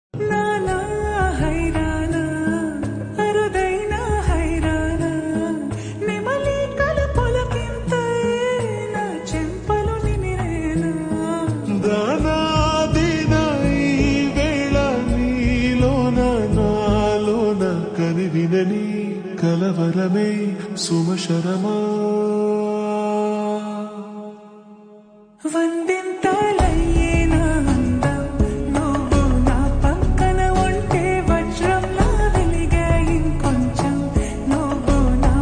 (Slowed + Reverb)